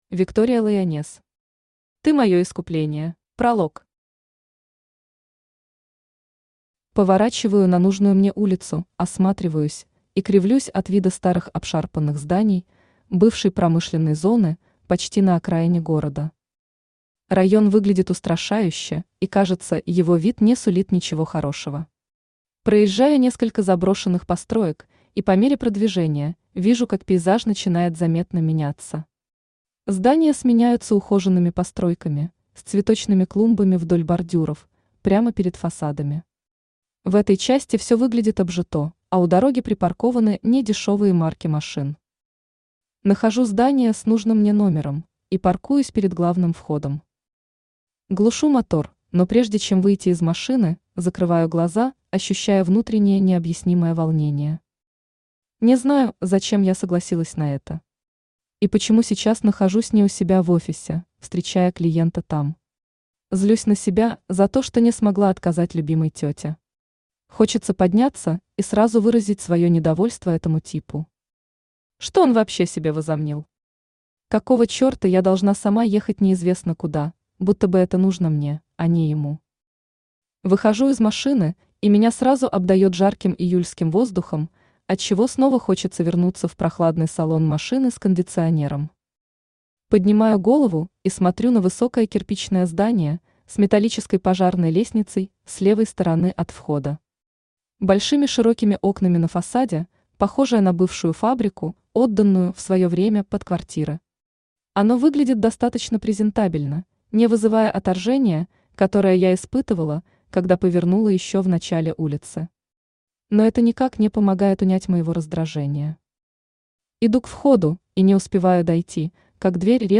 Аудиокнига Ты мое искупление | Библиотека аудиокниг
Aудиокнига Ты мое искупление Автор Виктория Лайонесс Читает аудиокнигу Авточтец ЛитРес.